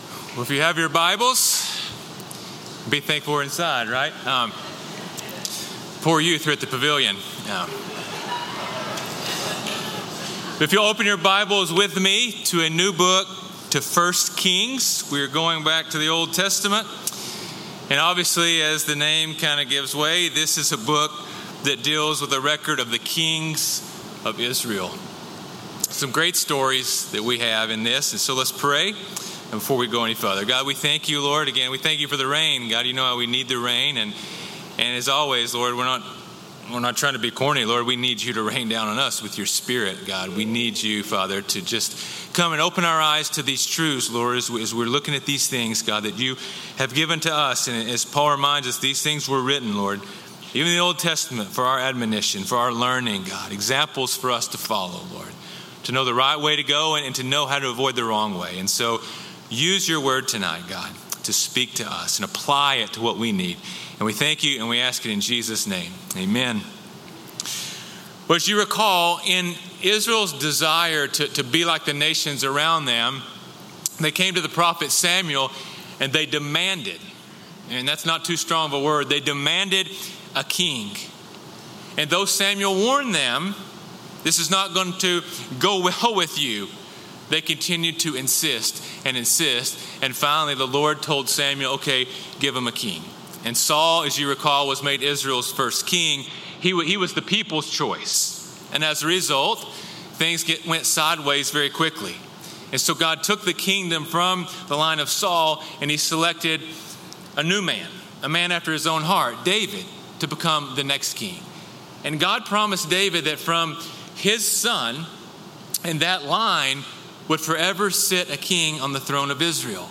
sermons 1 Kings 1:1-53 | Letting The Lord Exalt